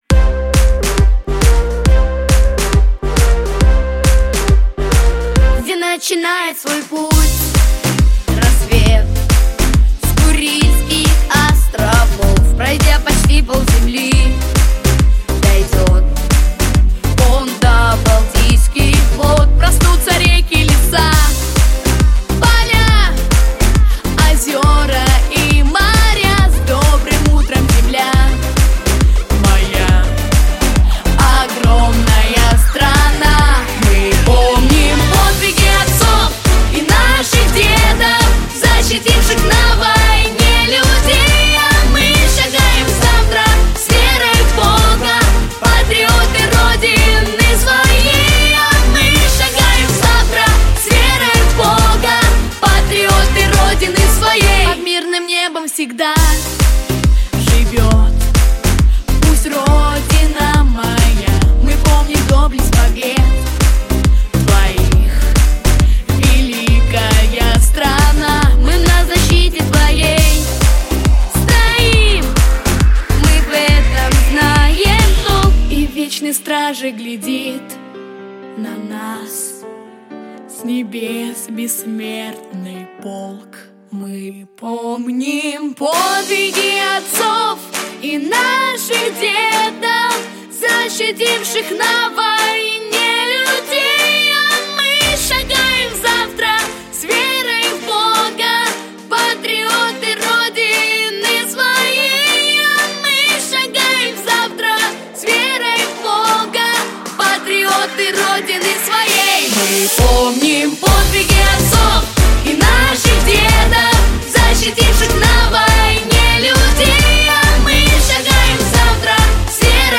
• Категория: Детские песни
патриотическая